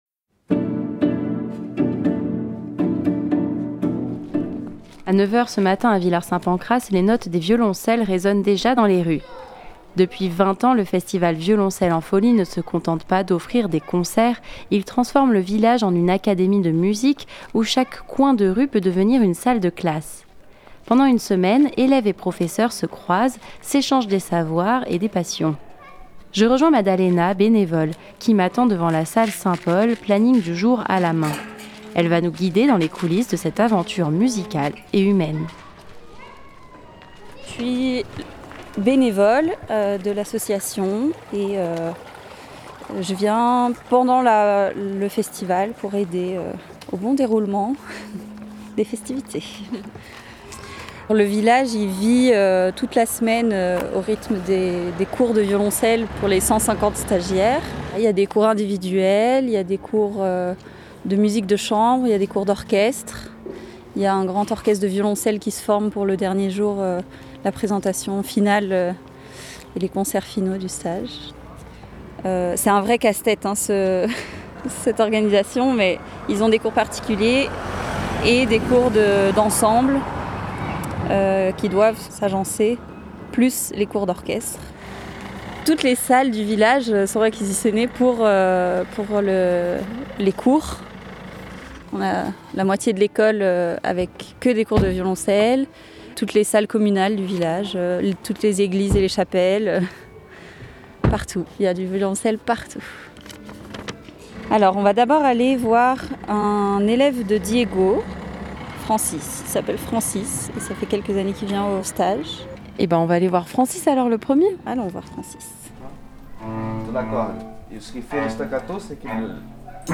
Ce matin-là à Villard-Saint-Pancrace, les notes des violoncelles résonnent déjà dans les rues.
Reportage Stages Violoncelle en folie (25.21 Mo)
Fréquence Mistral s'est rendu sur place, pour une émission spéciale depuis le parvi de l'Eglise Sainte Catherine puis à Villard Saint Pancrace pour découvrir l'ambiance du village pendant la semaine de stage.